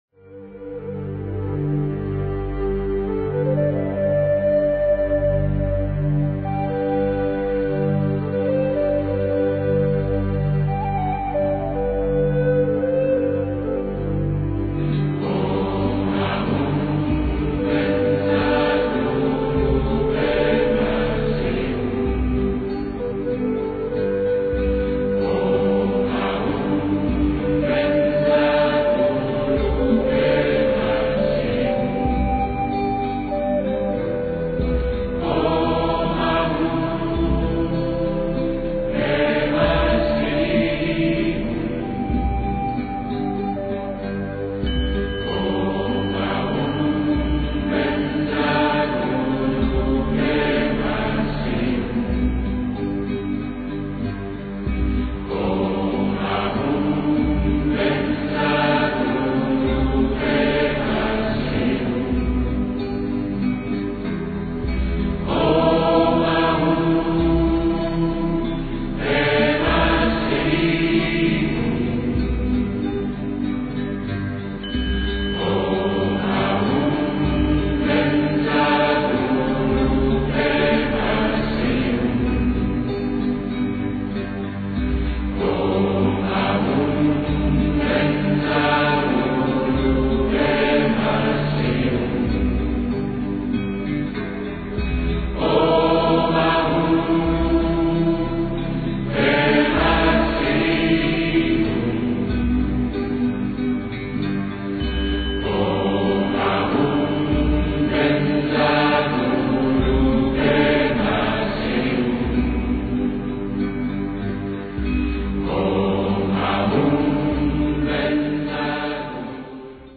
Zwei lange tibetische Mantren